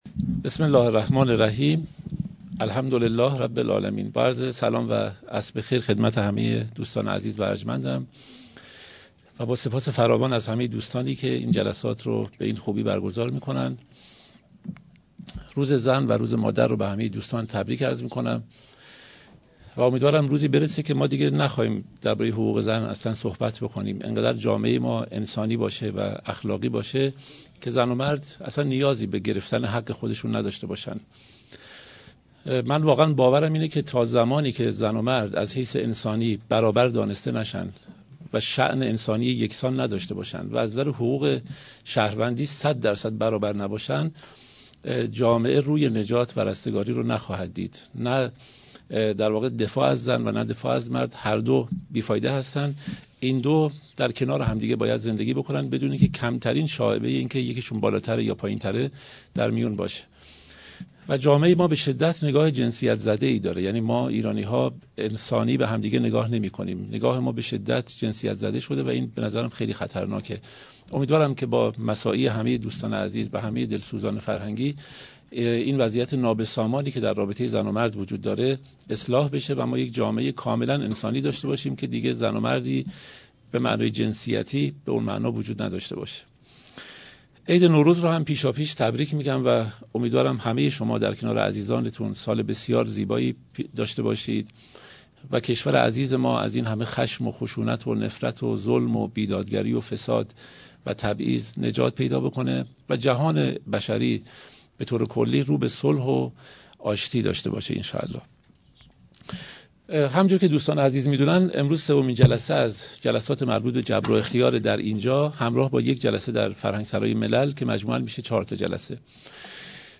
درسگفتارهایی درباره اندیشه مولانا
محل برگزاری: سالن حکمت